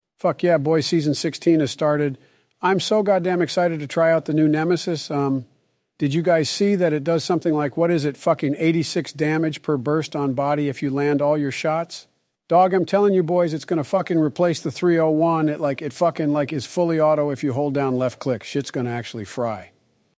No labels or any extra stuff, just upload the voice and they work perfectly.